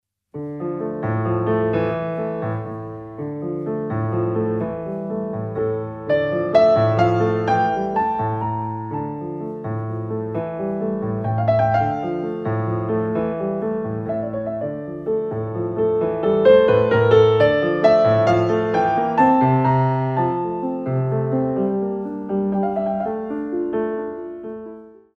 6/8 - 8x8+2x8